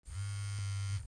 vibrate.wav